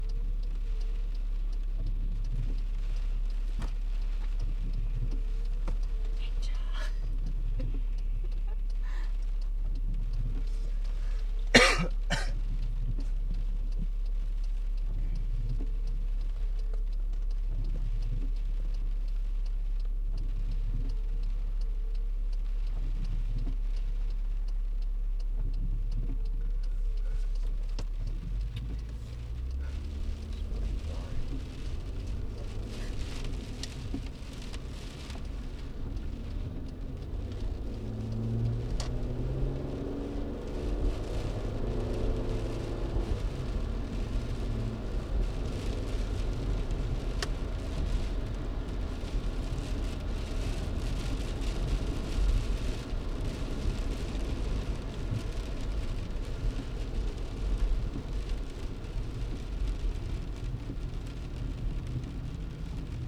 1) In a car leaving Hofstra and getting on to Hempstead Turnpike.
2) Rain hitting the windshield, whispering/ talking, laughing/ giggling, acceleration of car, blinker click noise and coughing.